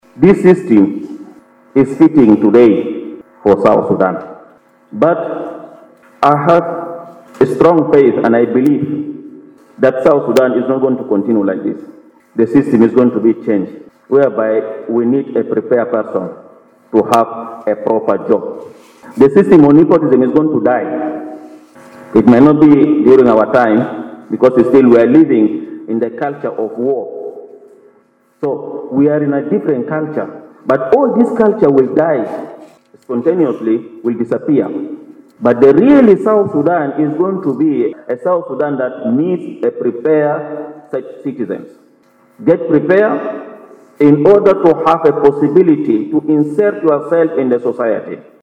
Bishop Matthew Remijo was speaking to the congregation on Sunday during the 32nd anniversary of the Holy Family Church in Wau